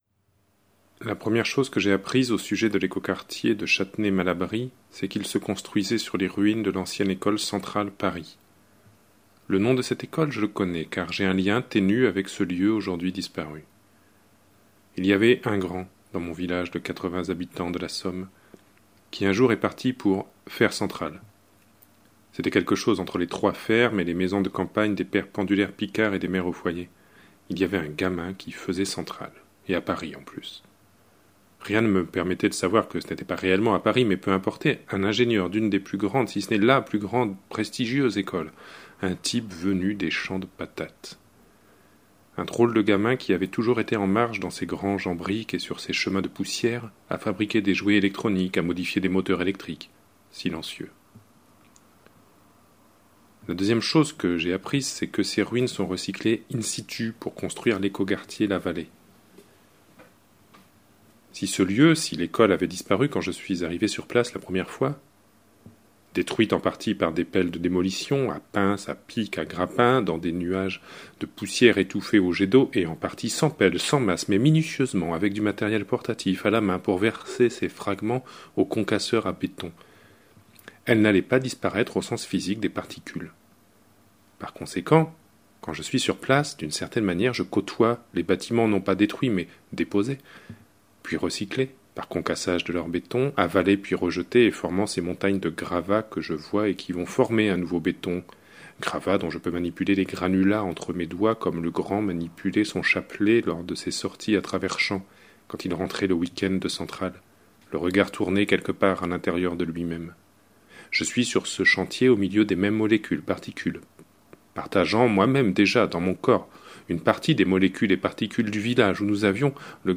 Extrait de Lisières limites, lu par